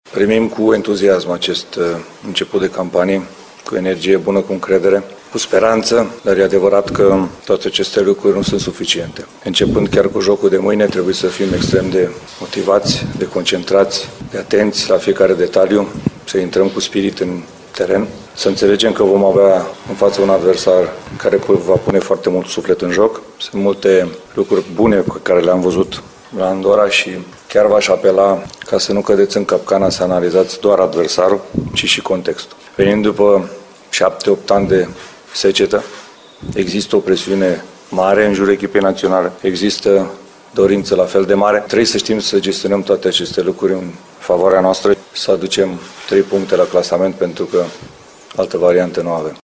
Iordănescu a făcut apel ca lumea să vadă întregul context în care se află tricolorii: